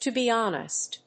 アクセントto be hónest (with you)